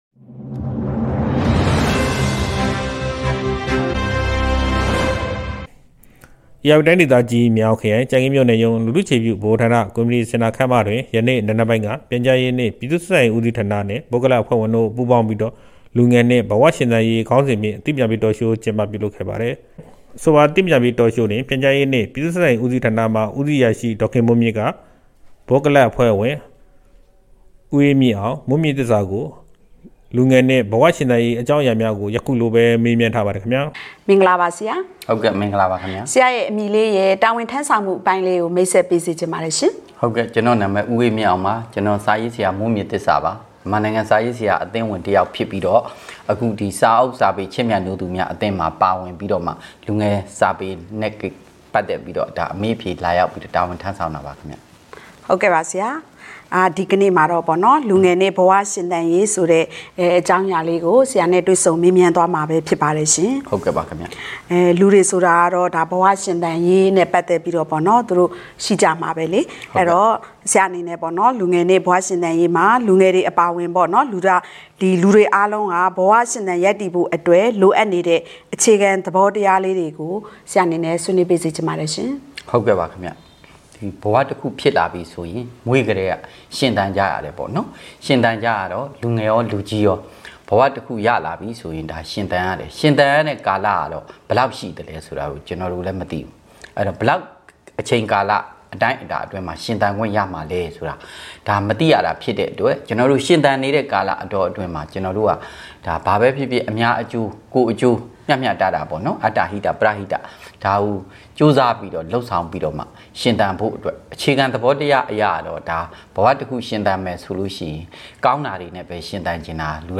လူထုအခြေပြုဗဟိုဌာနတွင် လူငယ်နှင့်ဘဝရှင်သန်ရေး အသိပညာပေး TAlk Show ရိုက်ကူးထုတ်လွှင့်